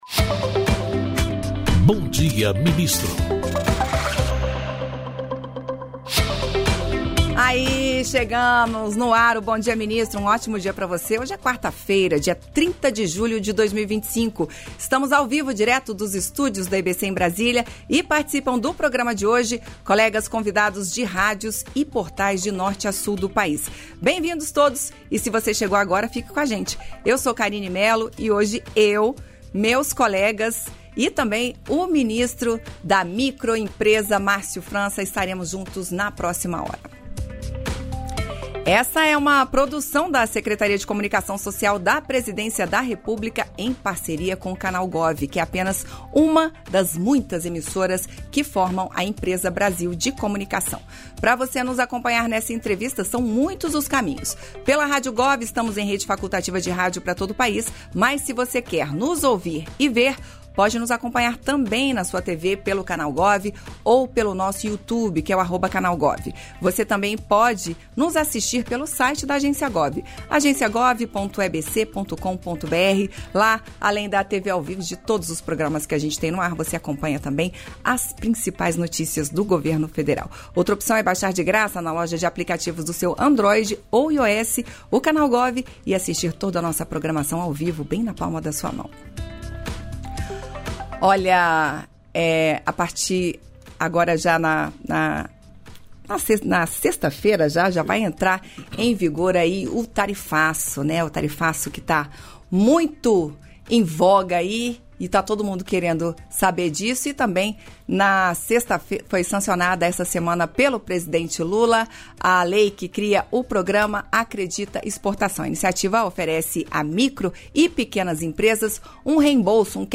Íntegra da participação do ministro do Empreendedorismo, da Microempresa e da Empresa de Pequeno Porte, Márcio França, no programa "Bom Dia, Ministro" desta quarta-feira (30), nos estúdios da EBC em Brasília (DF).